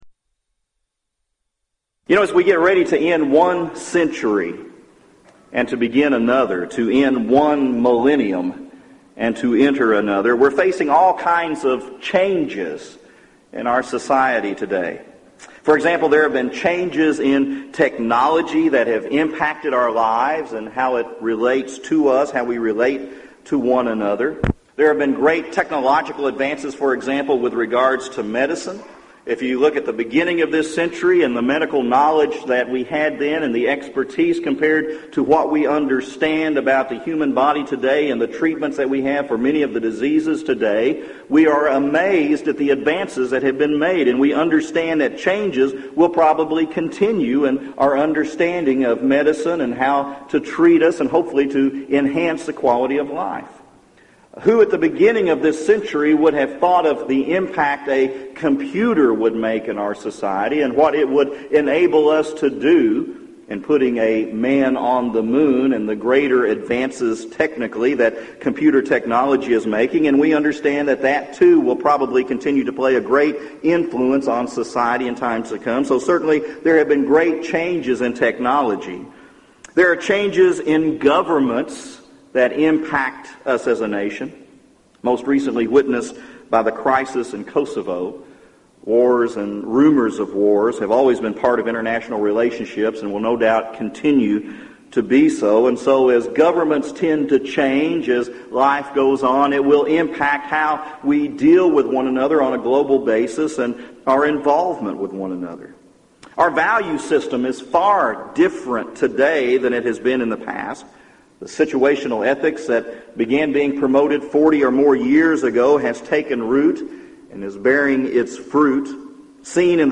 Series: Gulf Coast Lectures Event: 1999 Gulf Coast Lectures